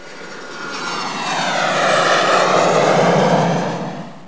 samolot2.wav